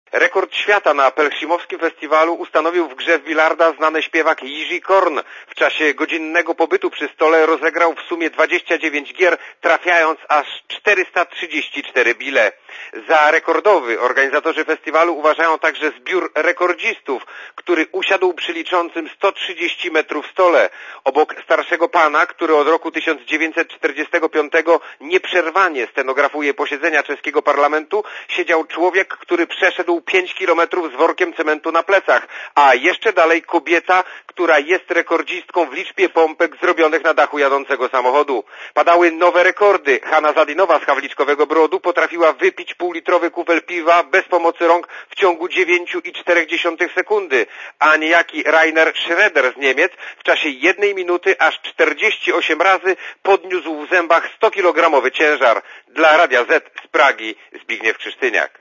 Posłuchaj relacji czeskiego korespondenta Radia Zet